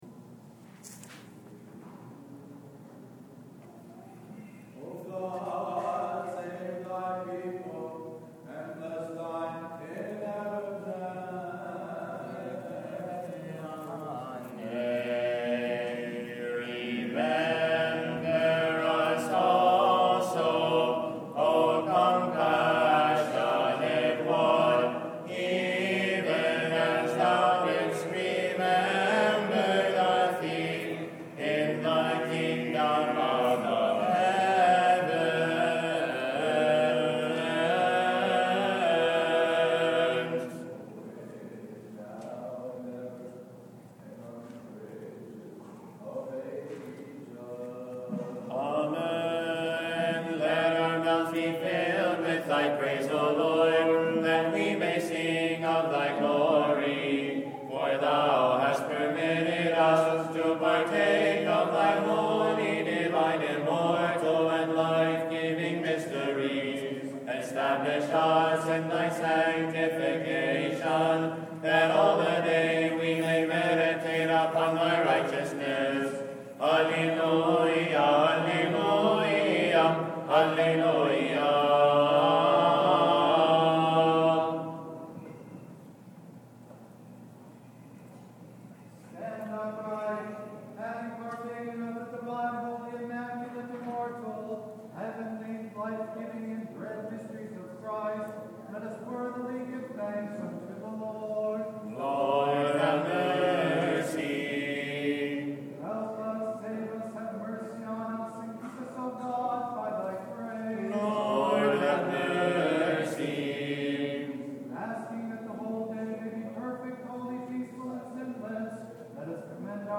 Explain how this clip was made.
Live Recordings from Services